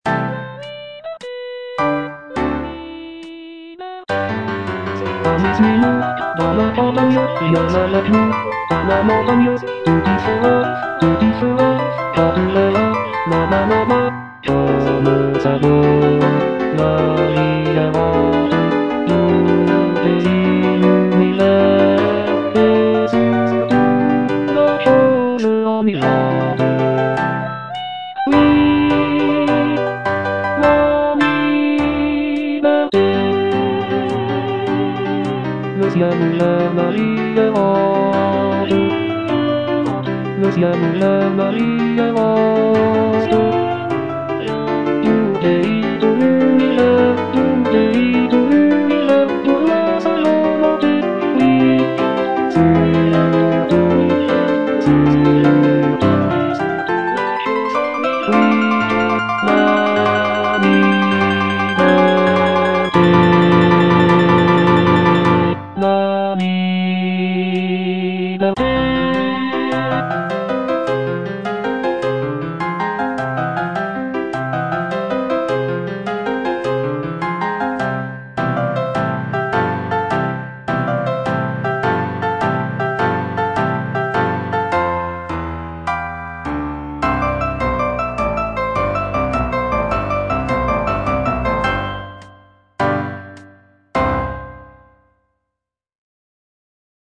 G. BIZET - CHOIRS FROM "CARMEN" Suis-nous à travers la campagne (bass II) (Voice with metronome) Ads stop: auto-stop